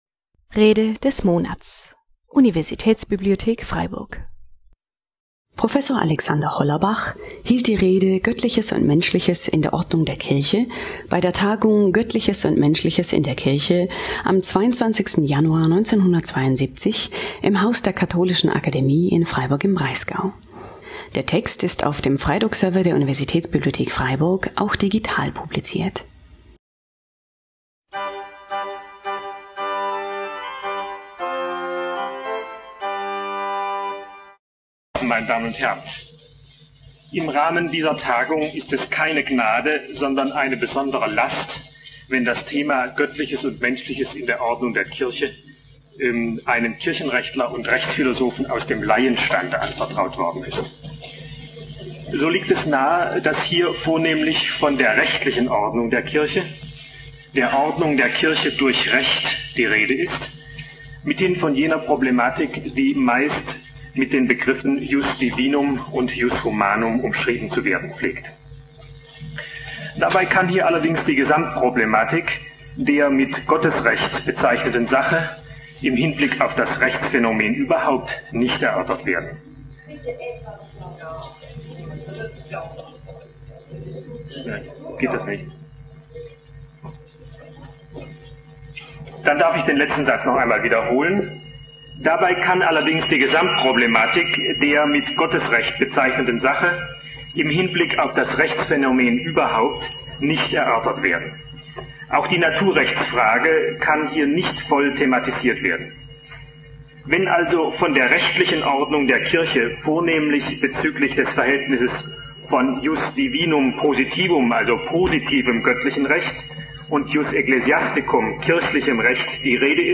Göttliches und Menschliches in der Ordnung der Kirche (1972) - Rede des Monats - Religion und Theologie - Religion und Theologie - Kategorien - Videoportal Universität Freiburg
Januar 1972 im Haus der Katholischen Akademie in Freiburg im Breisgau.